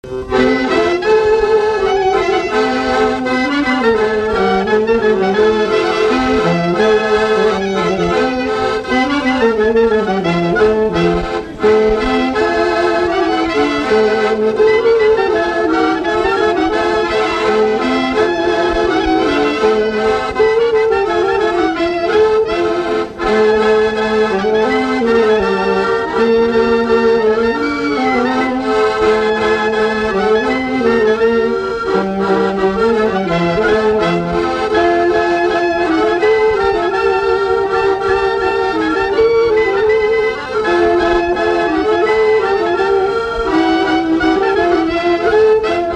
Scottish
Chants brefs - A danser
Résumé instrumental
danse : scottish (autres)